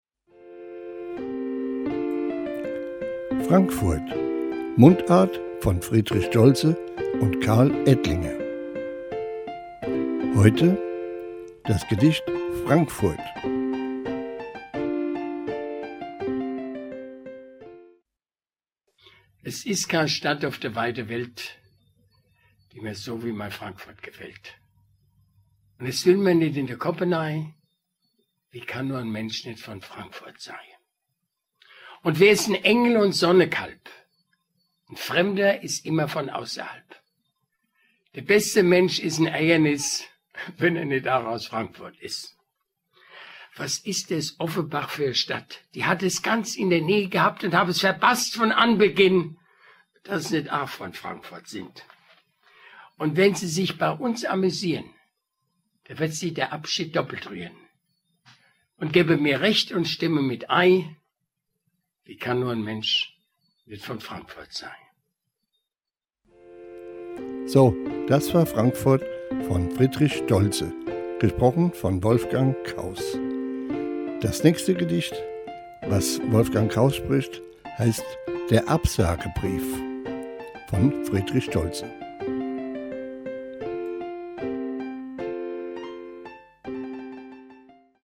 Mundart von Friedrich Stoltze "Frankfurt"
Gedicht: Frankfurt